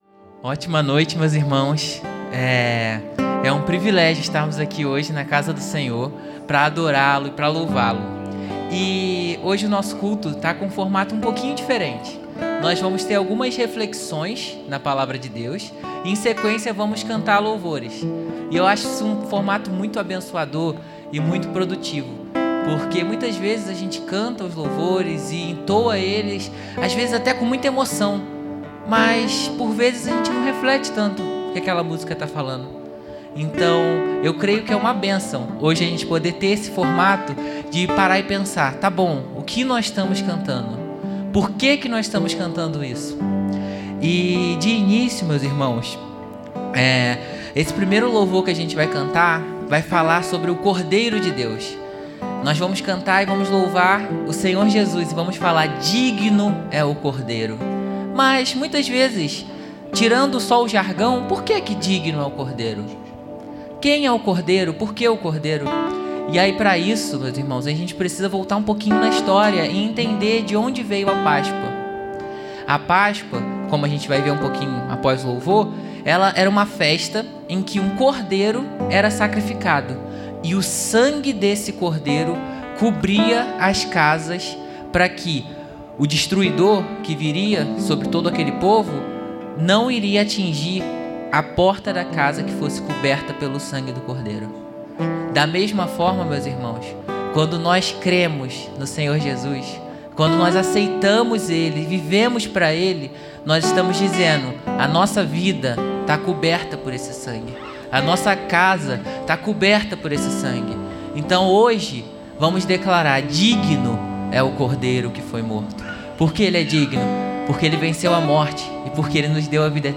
Culto de Domingo de Noite - 31-03-24